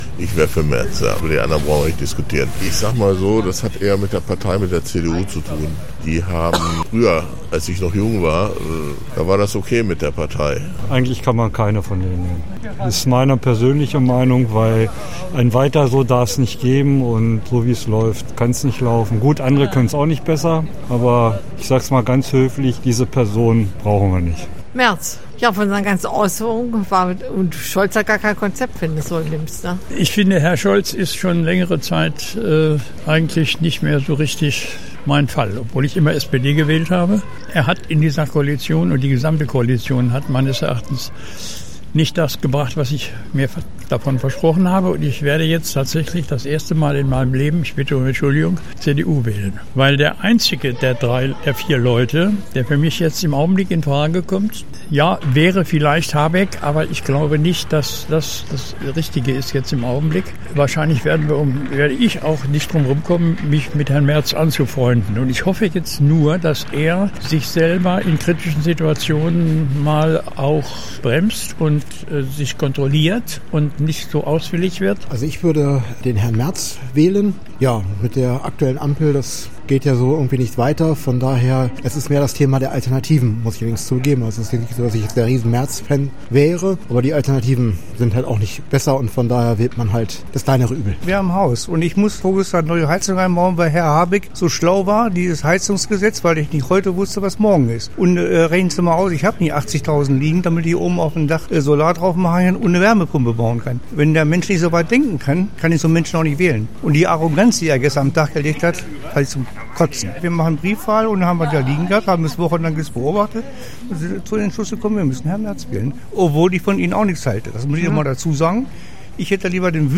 Umfrage: Wer hat sich am besten im Kanzlerduell geschlagen